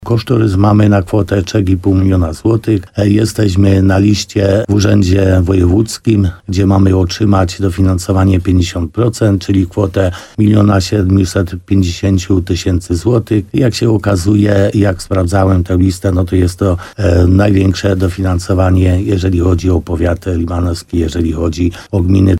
Jak powiedział w programie Słowo za Słowo w radiu RDN Nowy Sącz wójt Piotr Stach, ustalane są ostatnie kwestie dotyczące finansowania inwestycji.